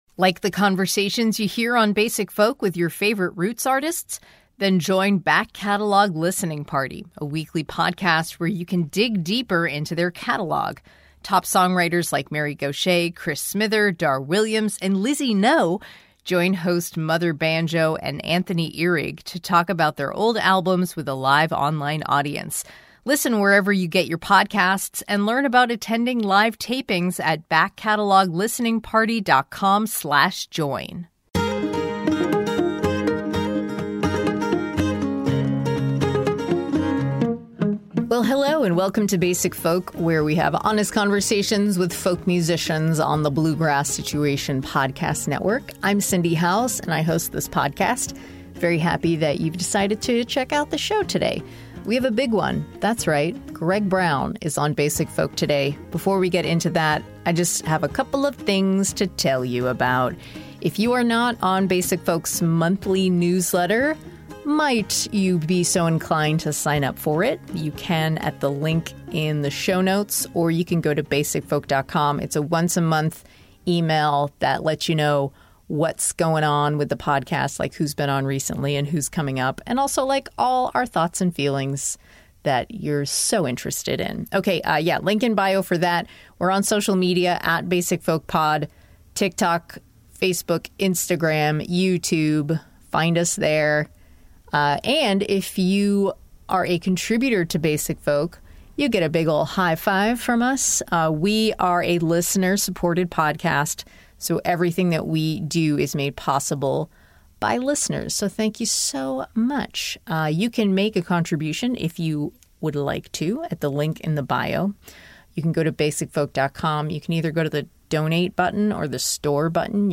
In our conversation, we touch on topics like inner peace, happiness, personal growth and self-acceptance.